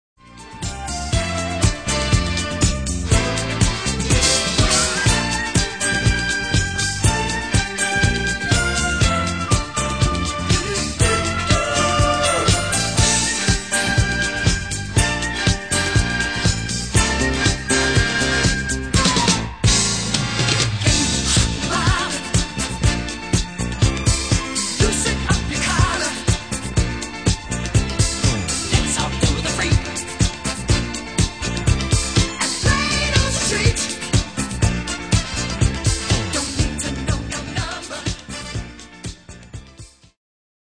Genere:   Disco | Funky | Miami Sound